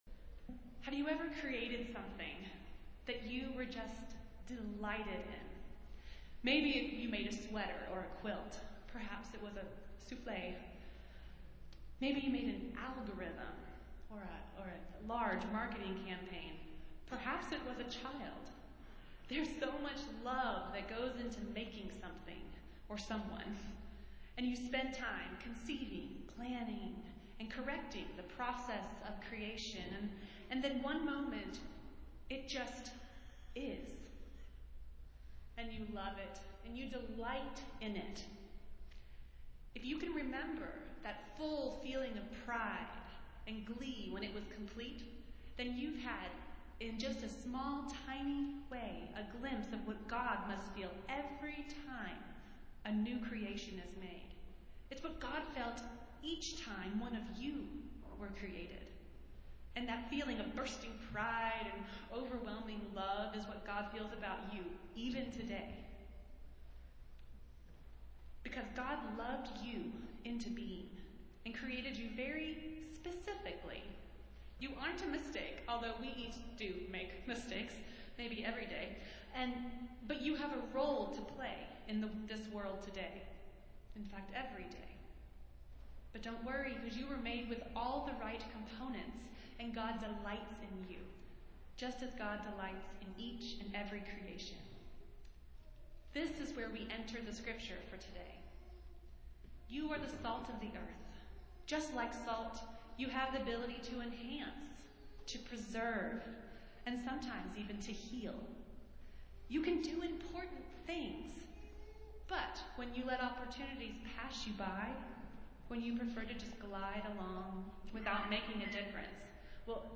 Festival Worship - Children's Sabbath